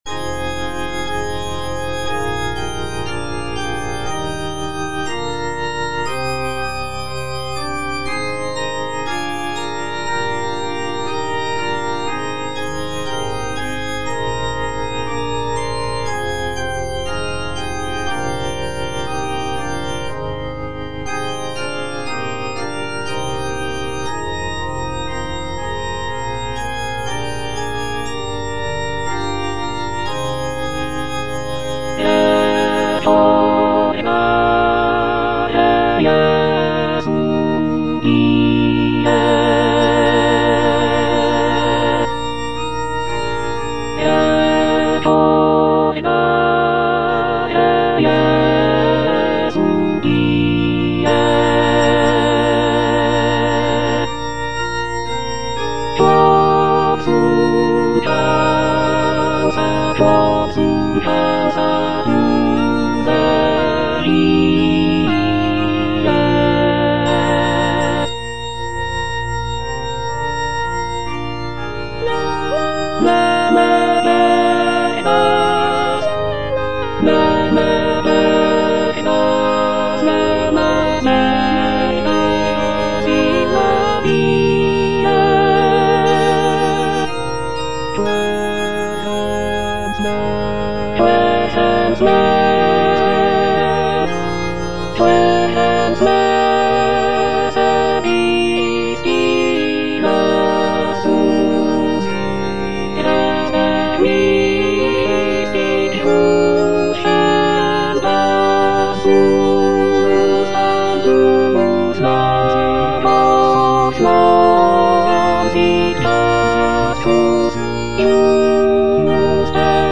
Alto (Emphasised voice and other voices) Ads stop
is a sacred choral work rooted in his Christian faith.